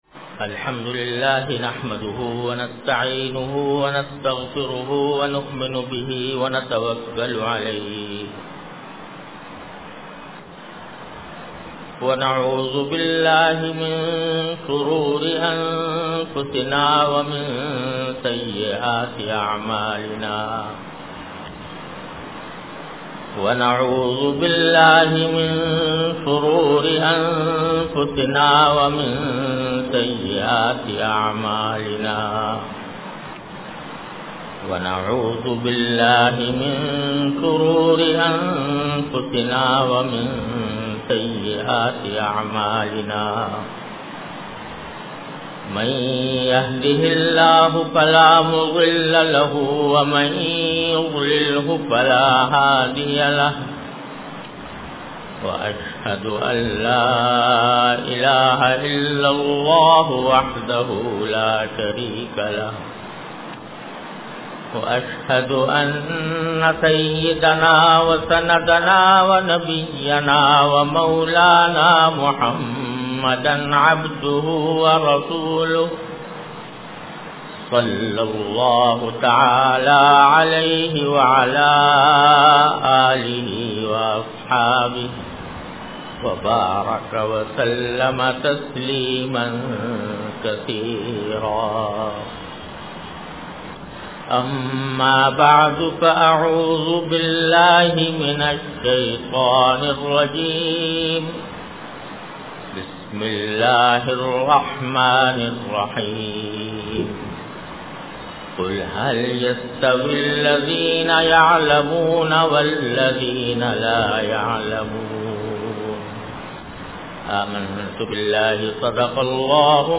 An Islamic audio bayan by Hazrat Mufti Muhammad Taqi Usmani Sahab (Db) on Bayanat.